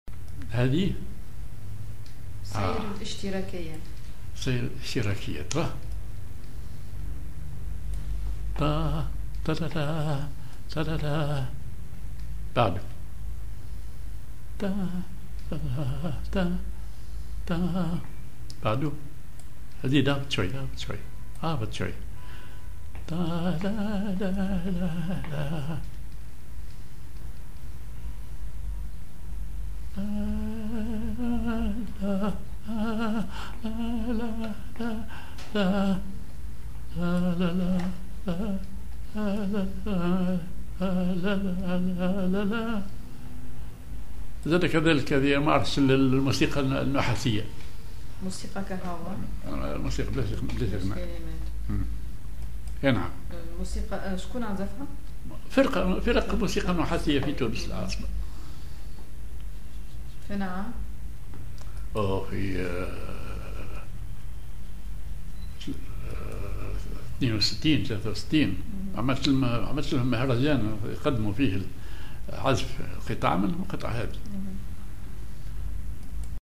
بوسلك نوا (أو صول صغير)
سير ذو نفس عسكري
genre أغنية